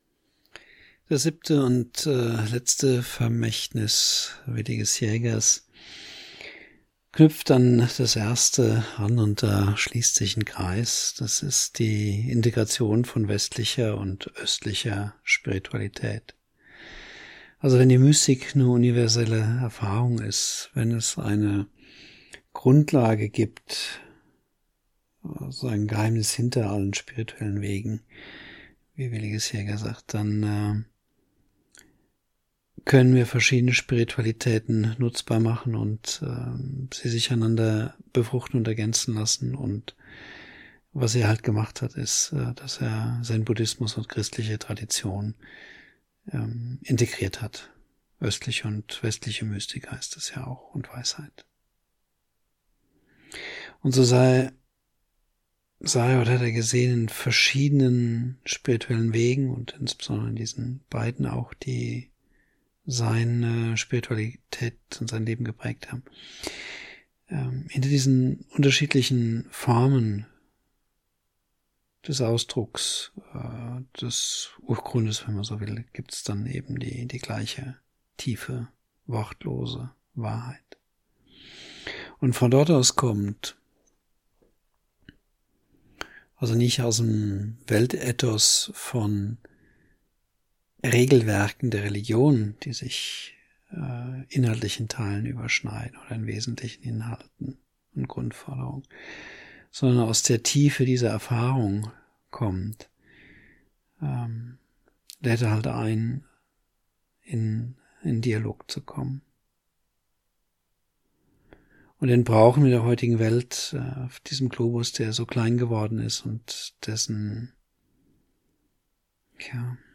In sieben Vorträgen erläutere ich mein Verständnis von den zentralen Punkten der Lehre und des Lebens von Willigis Jäger.